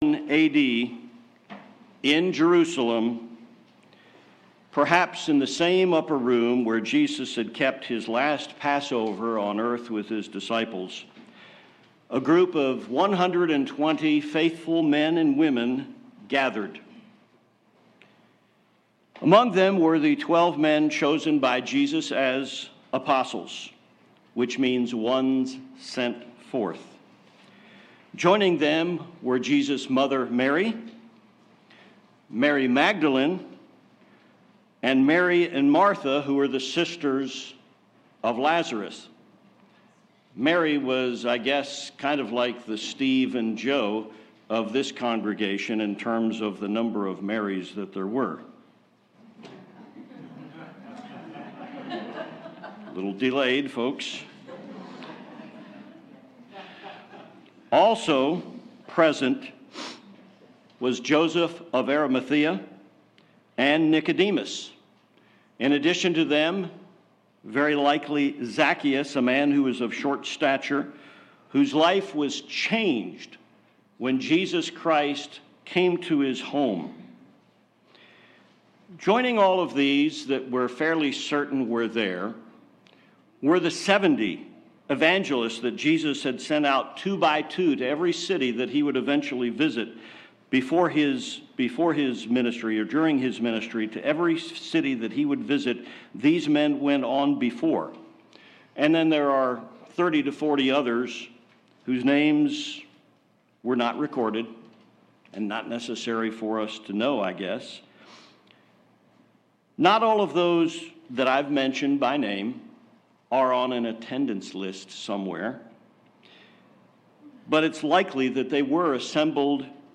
This is the second of a three-part series, given on Pentecost 2022. What is the reason for members of the Church of God and why have they been called now?